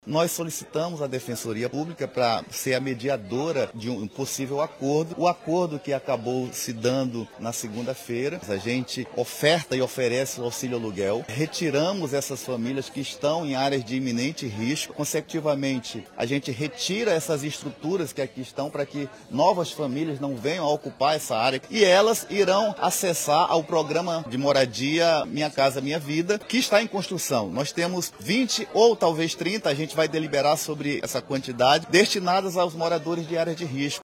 SONORA-1-JESUS-ALVES-.mp3